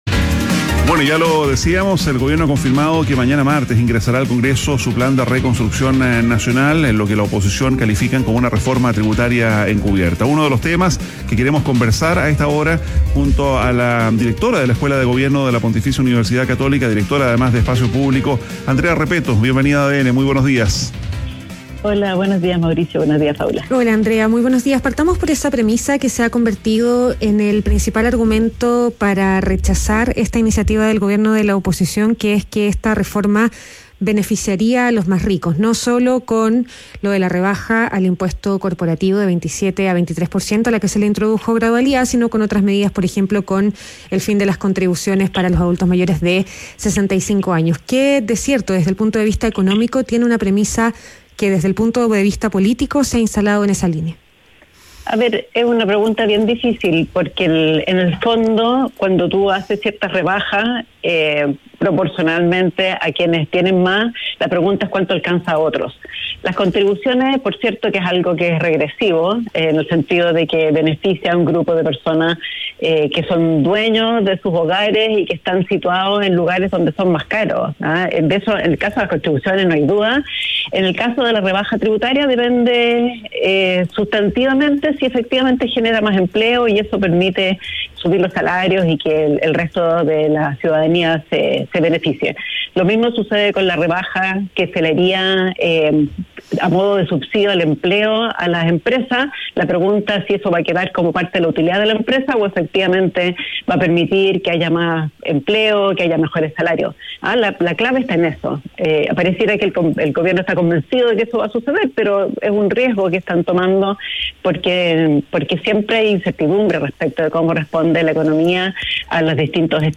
En conversación con ADN Hoy, la economista cuestionó la apuesta del Gobierno por bajar impuestos para reactivar la economía y alertó que el costo fiscal podría terminar afectando las finanzas públicas.
Entrevista